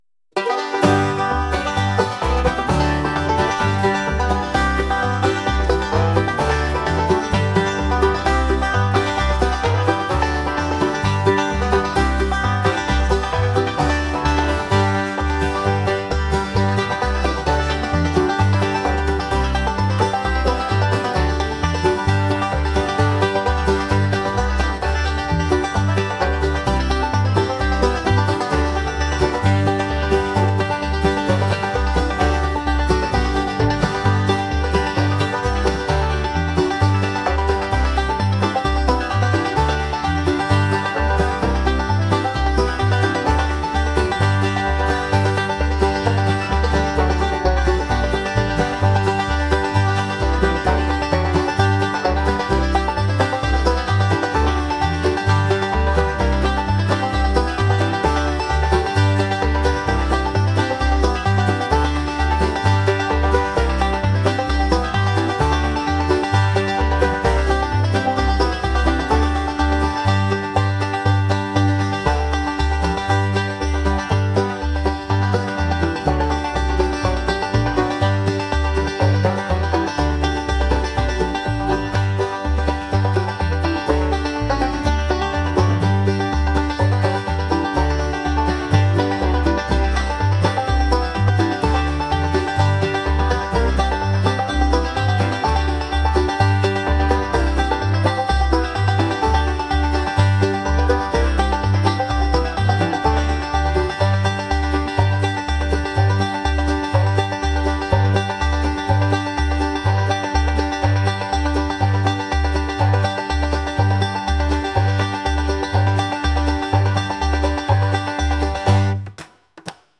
acoustic | lively